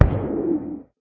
guardian_hit4.ogg